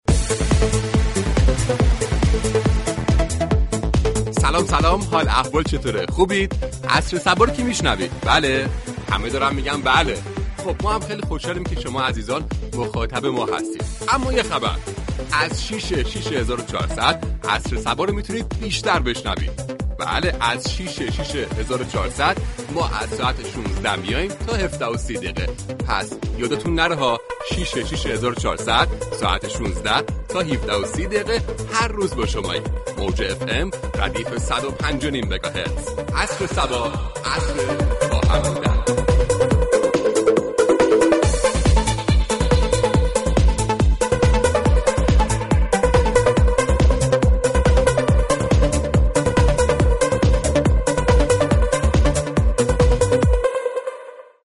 به گزارش روابط عمومی رادیو صبا ، برنامه "عصر صبا " كه در قالب مجله عصرگاهی با بیان موضوعاتی اجتماعی و حقوق شهروندی می پردازد روز دوشنبه به یك موضوع اجتماعی و فرهنگی كنترل خشم اختصاص می یابد.
برنامه عصر صبا با تعامل با مخاطبان راهكارهایی برای كنترل خشم كسانی كه زود از كوره در می روند ارائه می كند و از تاثیرات بد عصبانیت بر فرد و جامعه می گوید.